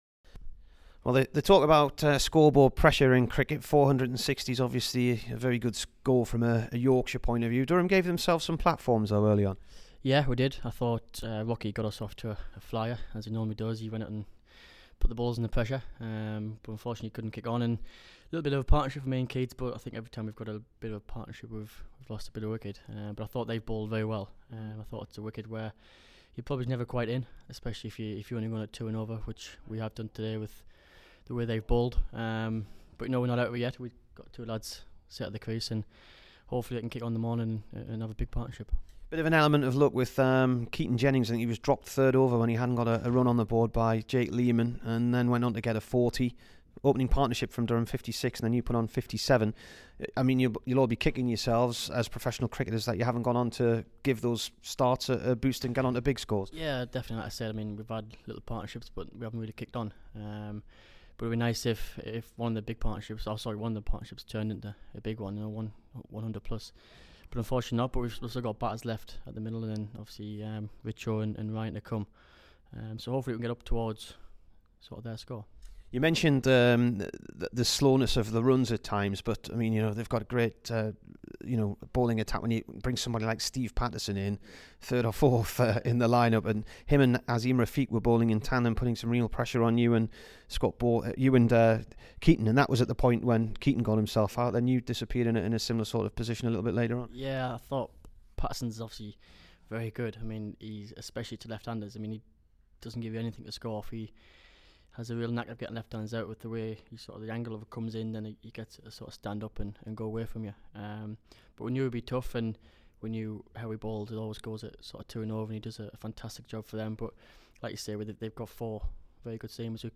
Here's the Durham all rounder after his 53 v Yorkshire.